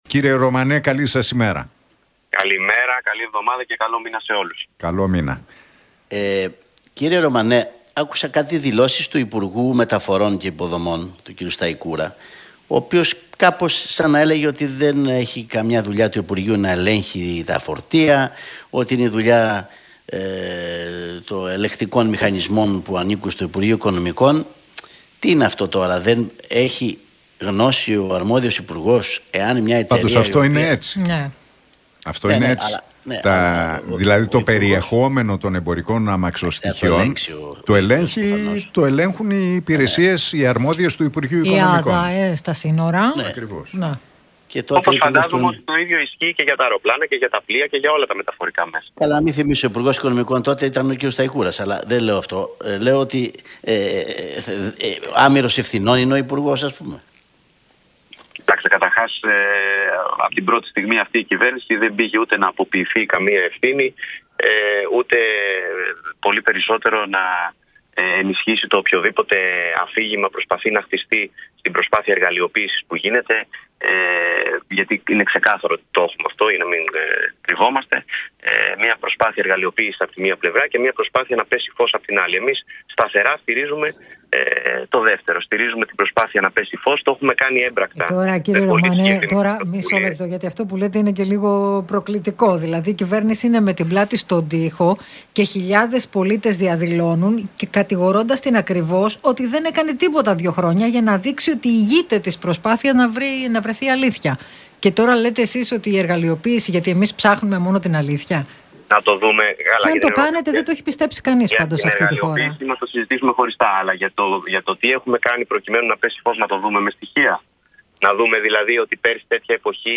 Για την υπόθεση των Τεμπών, τις δηλώσεις του Πρωθυπουργού αλλά και τα μέτρα που έχουν ληφθεί στον σιδηρόδρομο μίλησε ο εκπρόσωπος Τύπου της ΝΔ, Νίκος Ρωμανός